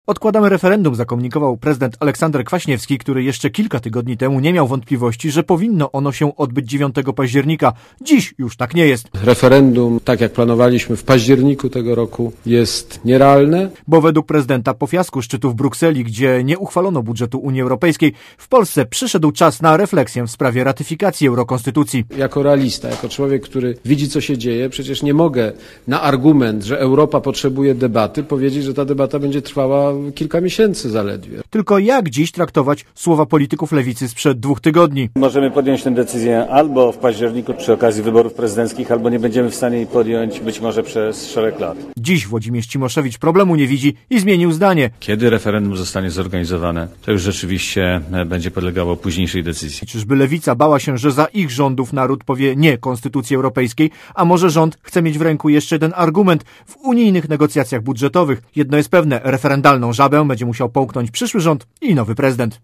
Relacje